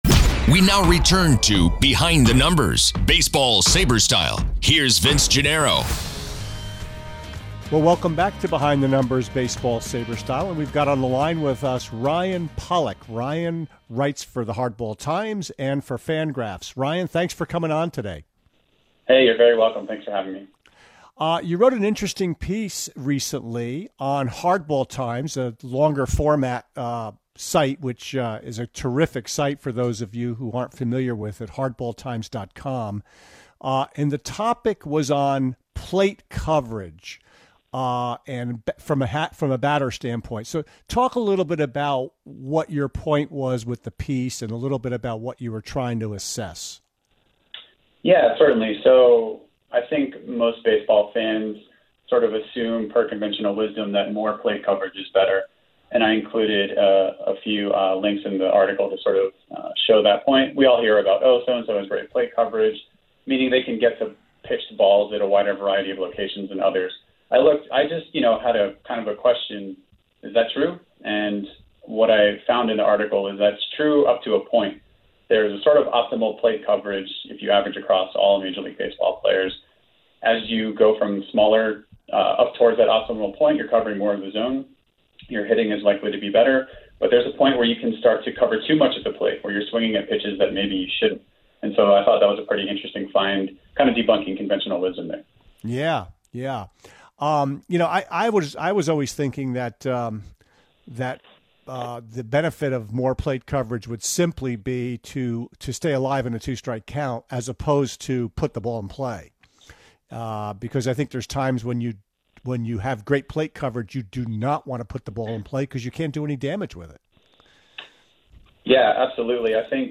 Cubs Dynasty, Plate Coverage, and More: Full Audio from my MLB Network Radio Interview